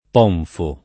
ponfo [ p 0 nfo ] o pomfo [id.]